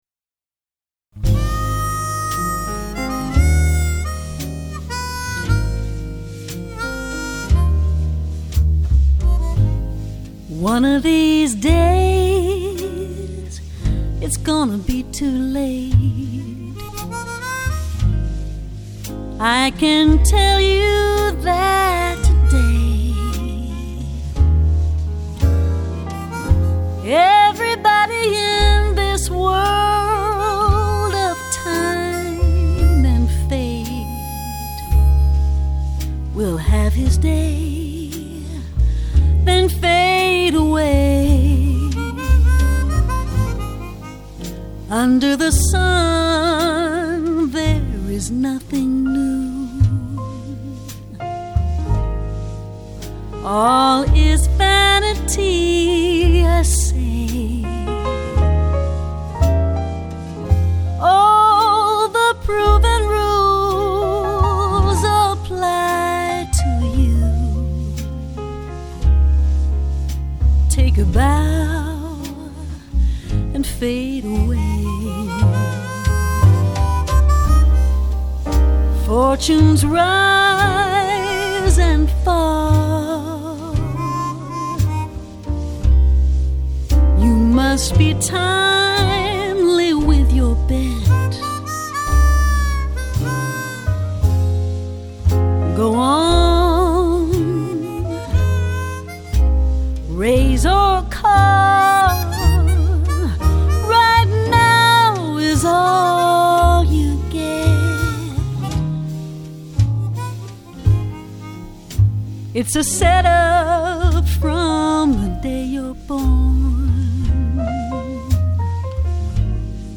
recorded in Nashville